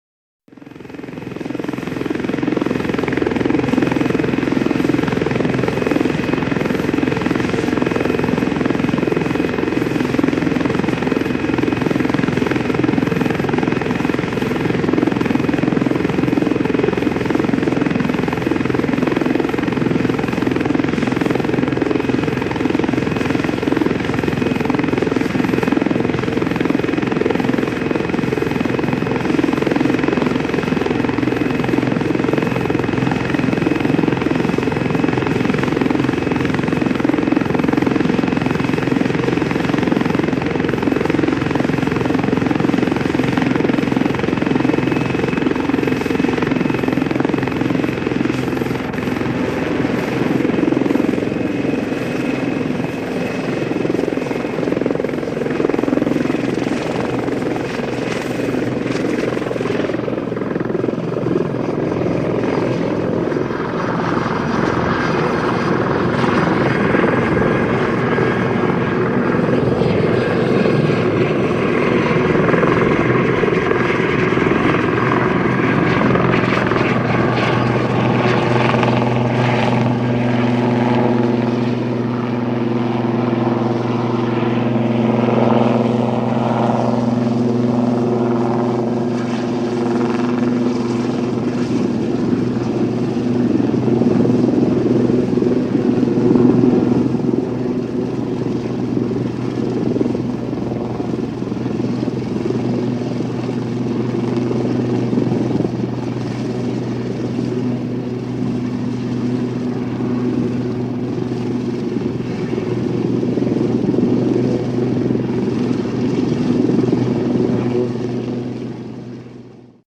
Jungle in the studio with some cars